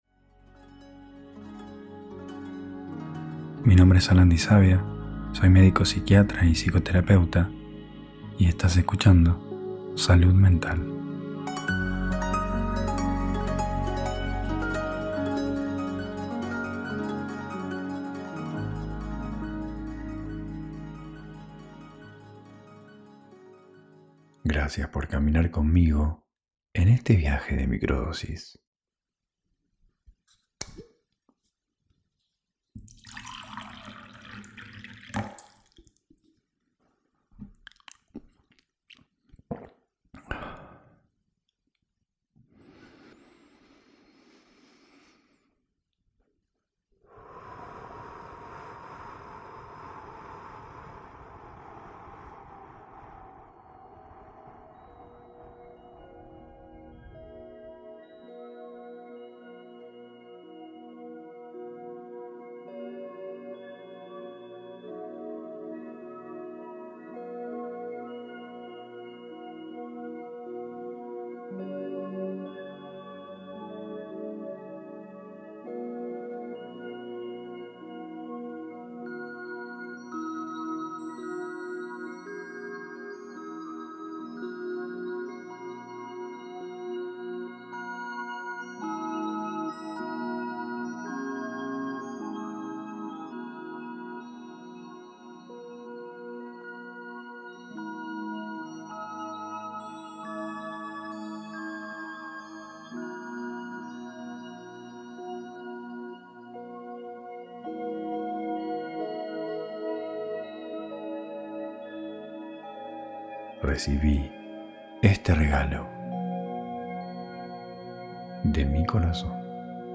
Ritual de Microdosis guiado · Incomodidad